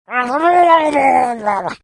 This is a murloc.
murloc.mp3